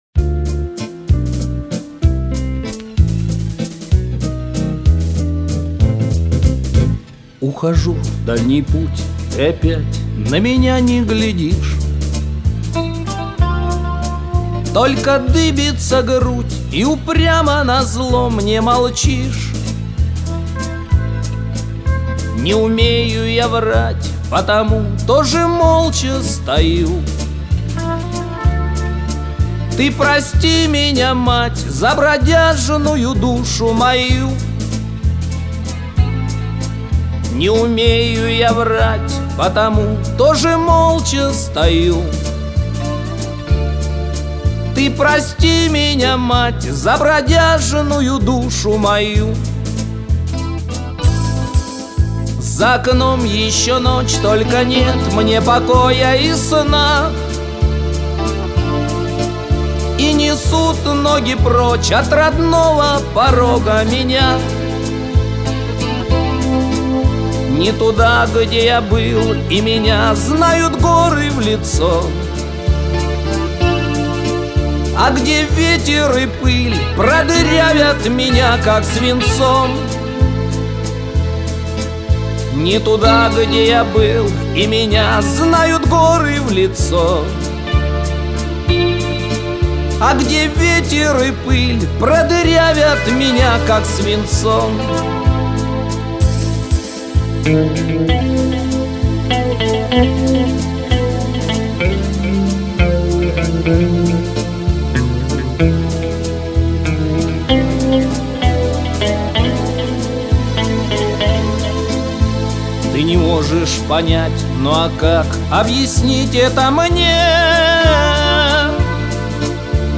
Шансон
И голос протяжный, как в тех песнях про журавлей.